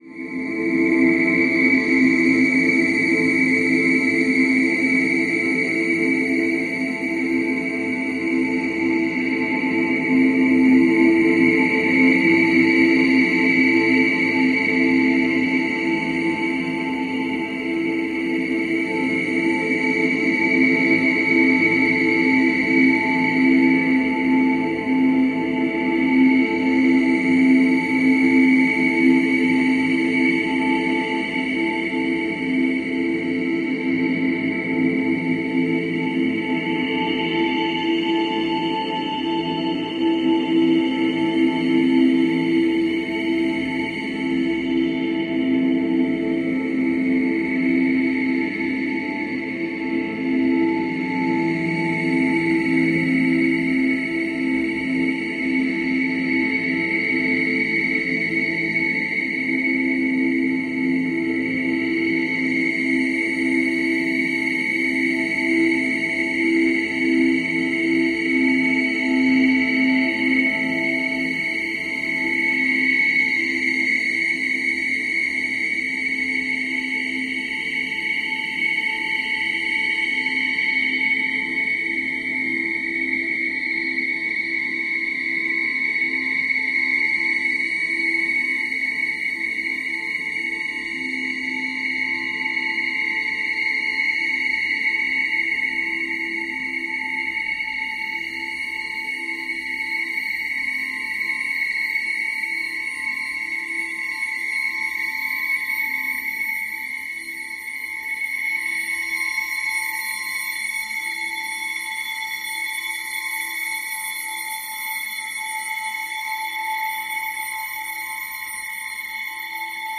Ambiance atmosphere sub zero